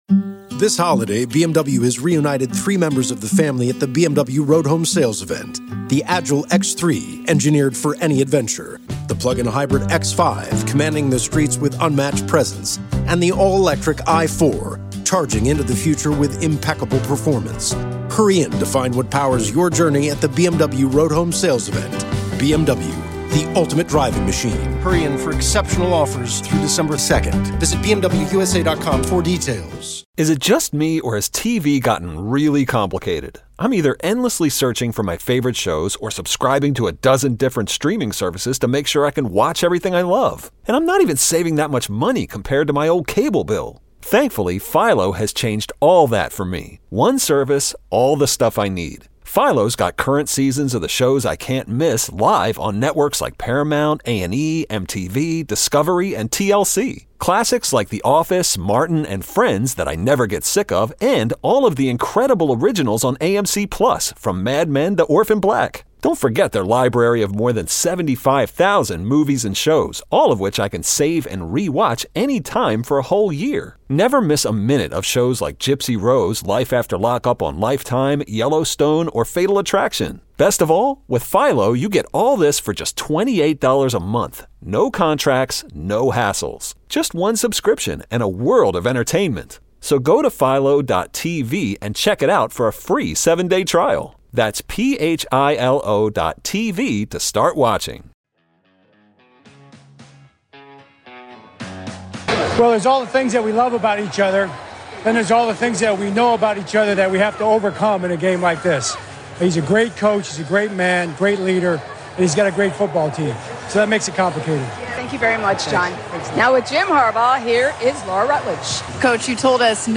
When it comes to sports talk in the morning, especially Bills and Sabres, there is only once source in Buffalo...